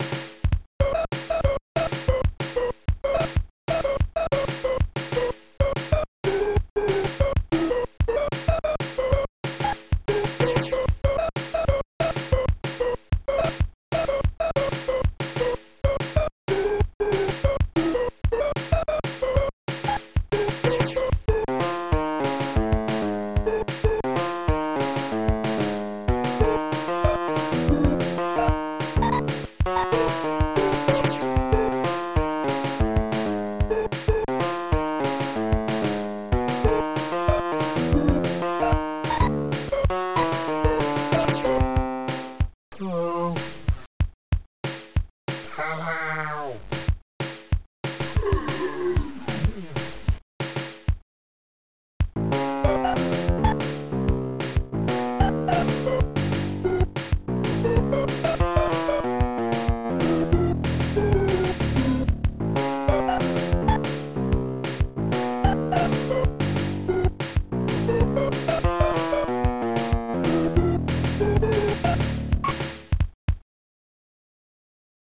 Covers of Lincoln-era B-sides.